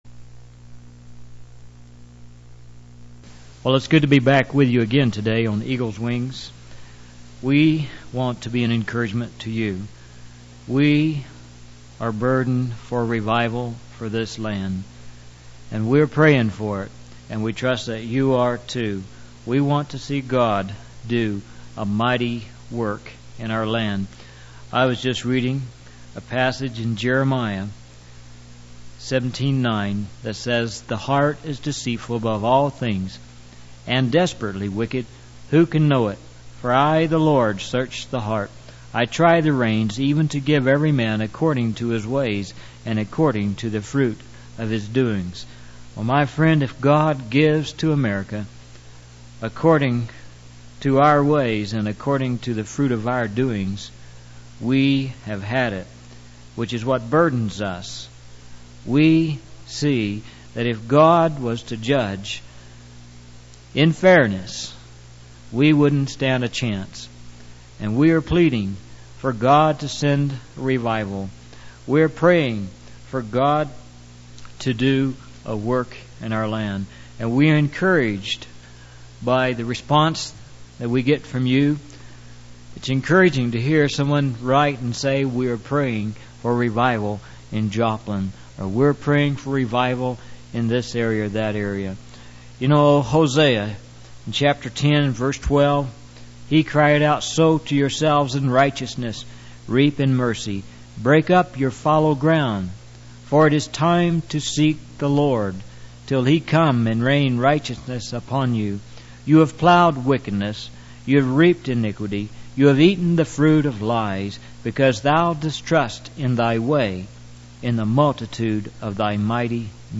In this sermon, the preacher shares a powerful testimony of a revival that took place in a village.